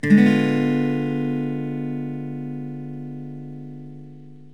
Gsdim7.mp3